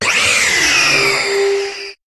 Cri de Necrozma Ailes de l'Aurore dans Pokémon HOME.
Cri_0800_Ailes_de_l'Aurore_HOME.ogg